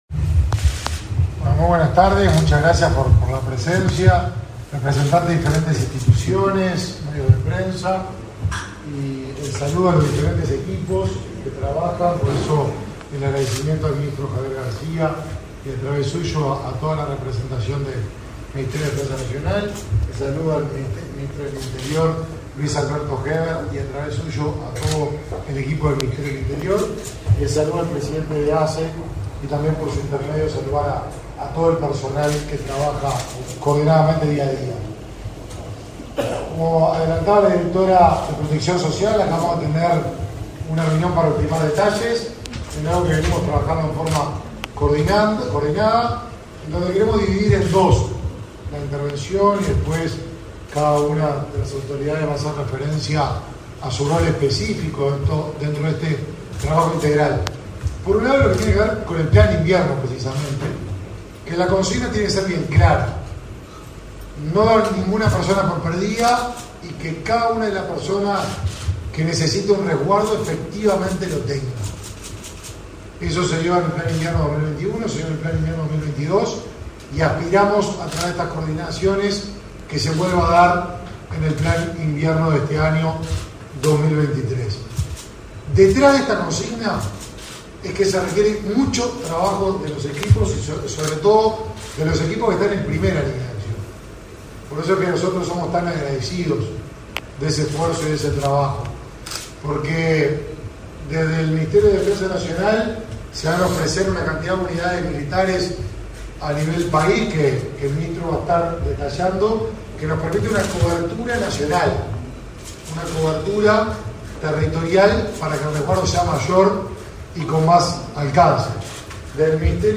Conferencia de prensa por acciones del Plan Invierno